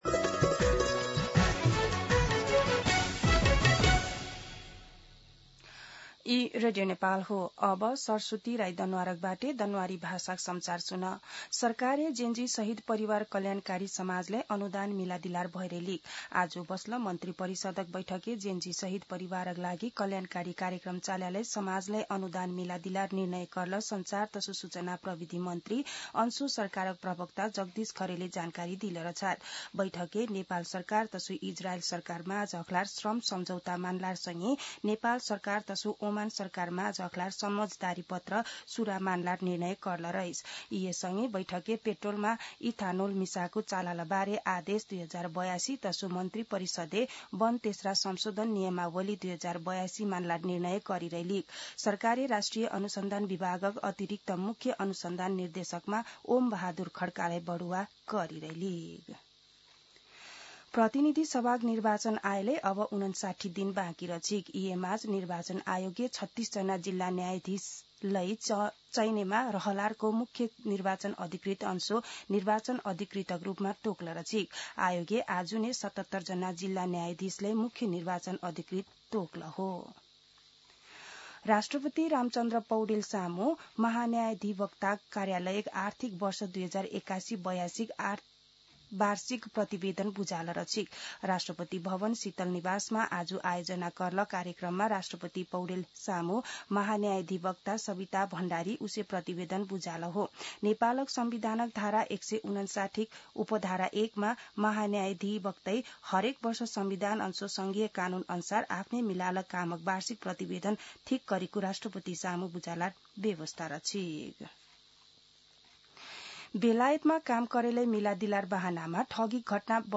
दनुवार भाषामा समाचार : २१ पुष , २०८२